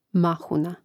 màhuna mahuna